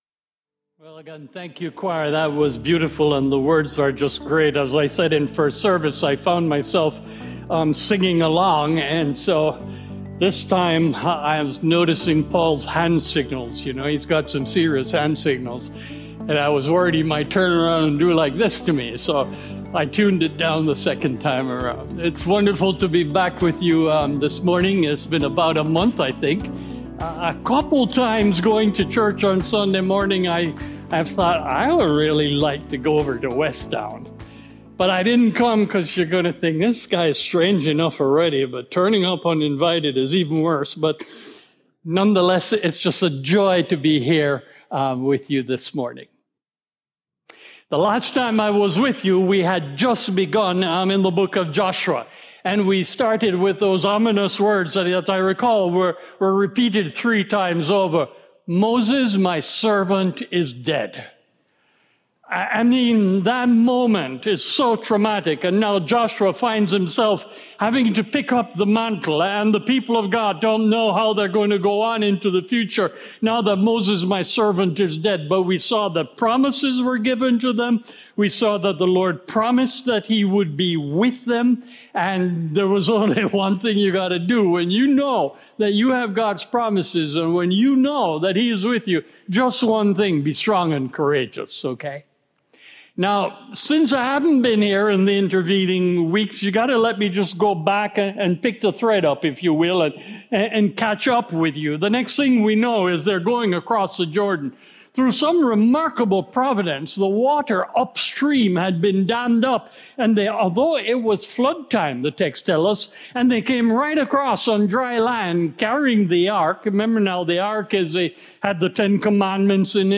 Join us in this 13 week sermon series through Joshua and discover what it looks like to find our strength and courage in the Lord!